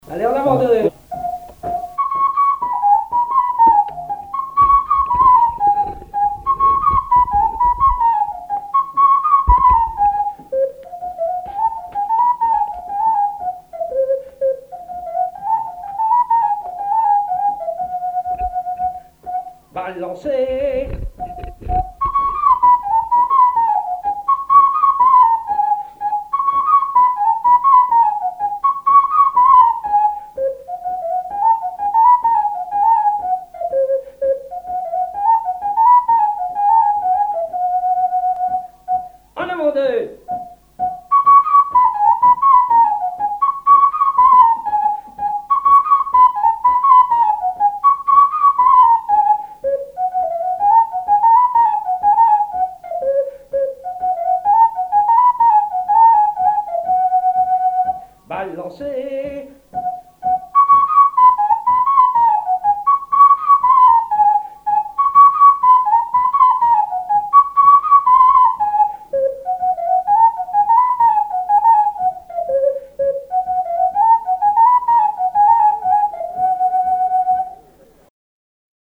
Mémoires et Patrimoines vivants - RaddO est une base de données d'archives iconographiques et sonores.
Résumé instrumental
danse : branle : avant-deux
Pièce musicale inédite